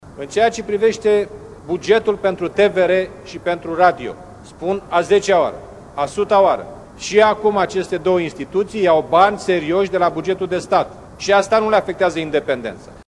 Inițiatorul legii, Liviu Dragnea, consideră că acest pachet legislativ poate fi susținut financiar: